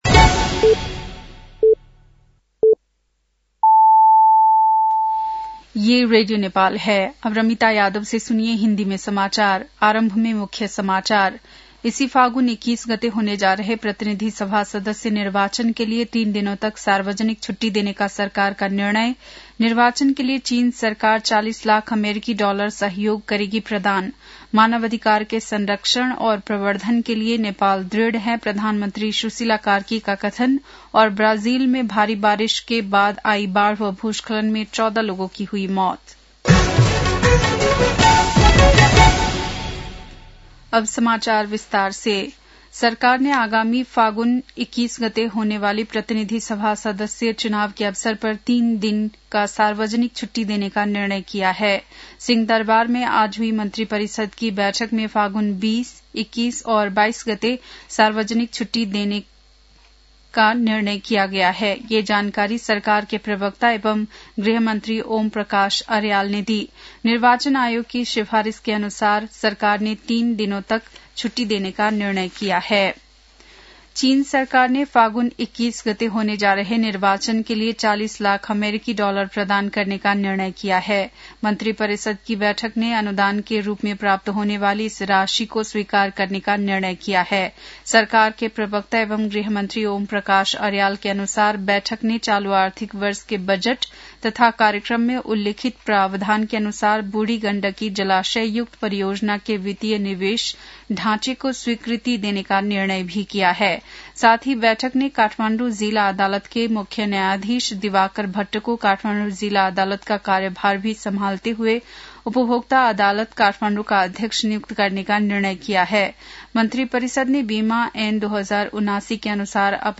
बेलुकी १० बजेको हिन्दी समाचार : १२ फागुन , २०८२